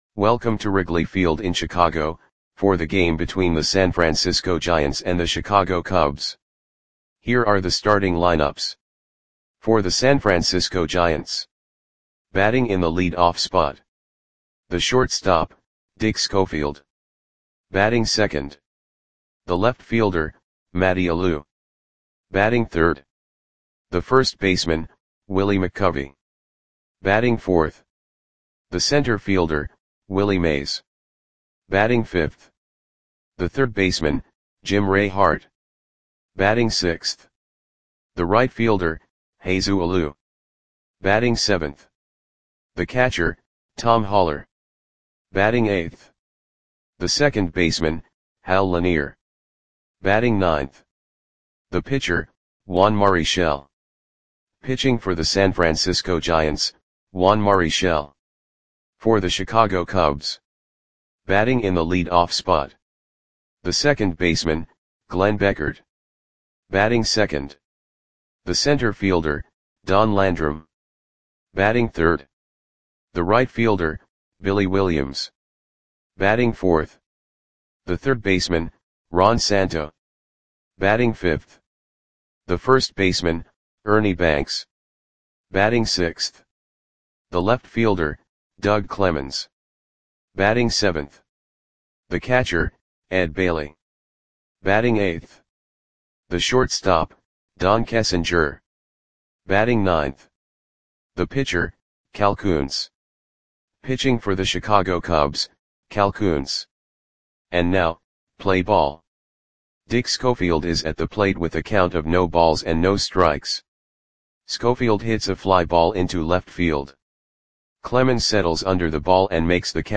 Audio Play-by-Play for Chicago Cubs on July 2, 1965
Click the button below to listen to the audio play-by-play.